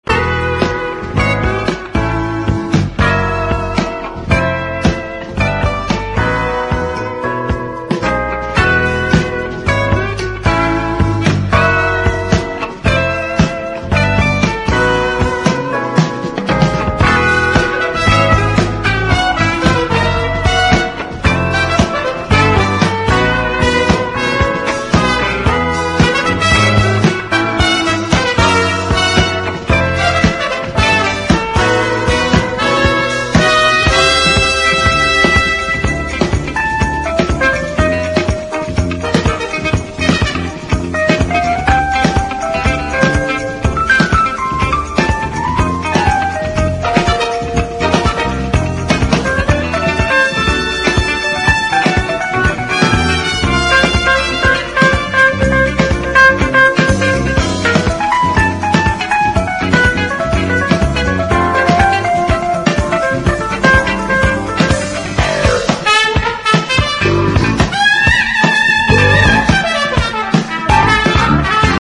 JAZZ / JAPANESE / FUSION
メロウネスを内包したコズミック/クロスオーヴァー・ジャズ・ファンク！
コズミック度高めの鍵盤プレイもソソります。